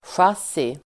Ladda ner uttalet
Uttal: [sj'as:i]